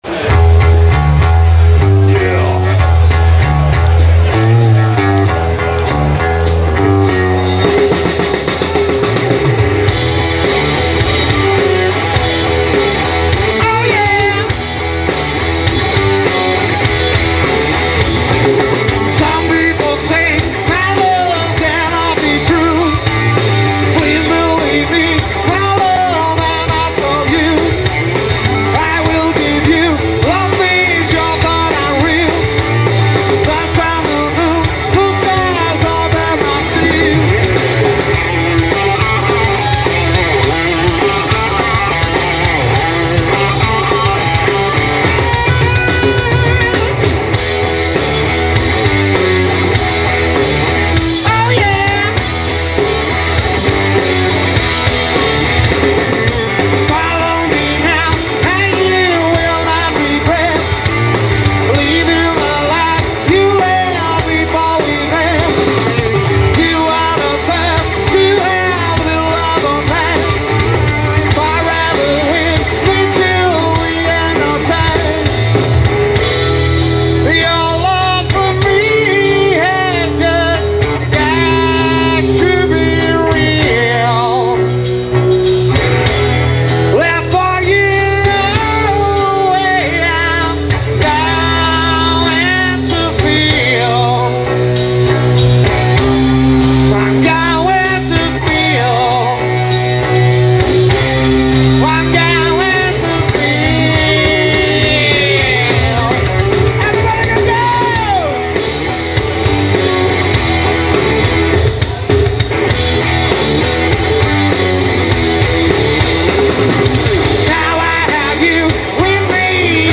Live hos Hot Rat MC i Vetlanda 2003: